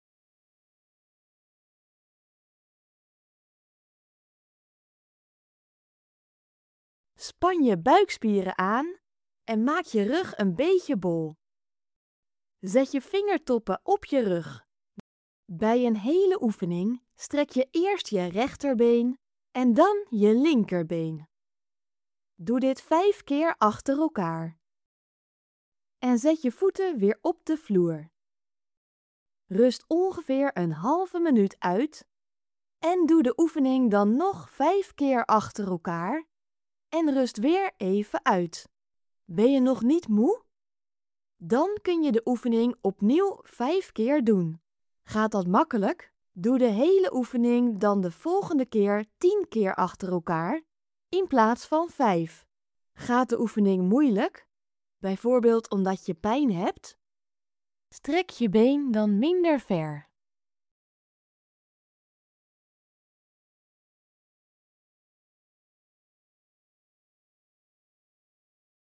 Geluidsbestand voor als je slecht ziet of blind bent